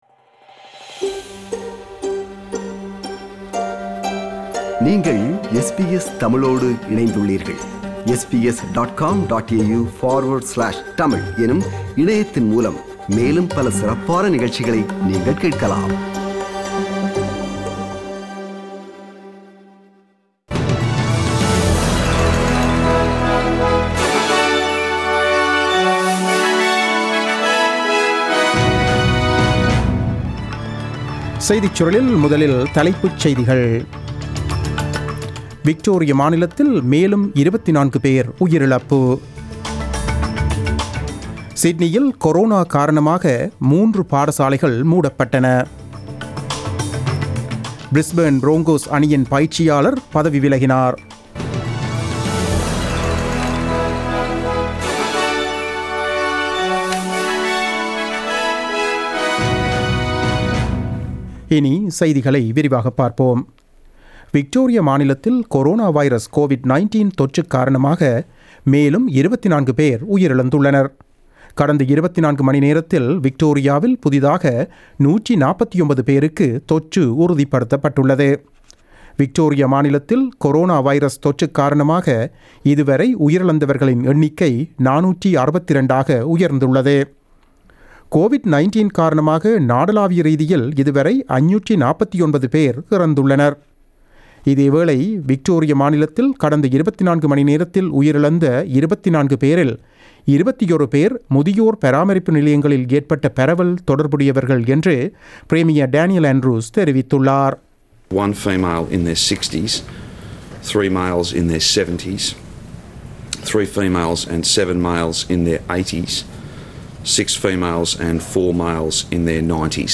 The news bulletin broadcasted on 26 August 2020 at 8pm.